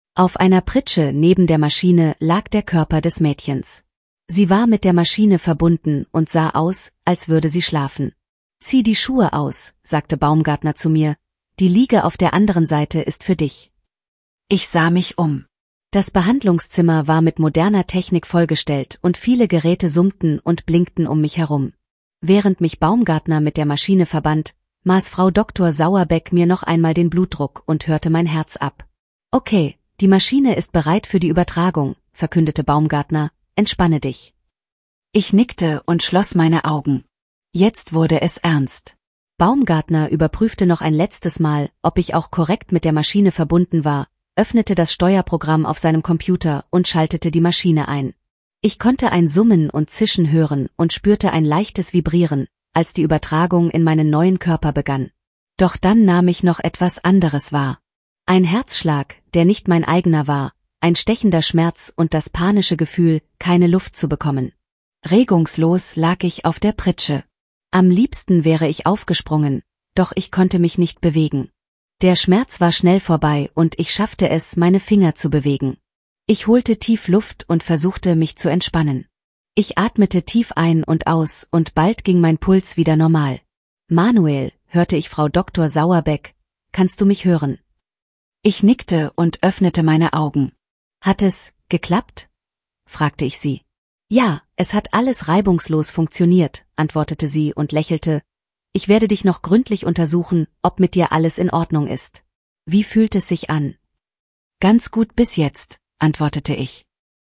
Text to Speech
Diesen Text habe ich nun von verschiedenen KI-Werkzeugen lesen lassen.
Ich habe die Stimmen Vivienne und Seraphina probiert.
speechma_audio_Seraphina-German_at_6_57_5.mp3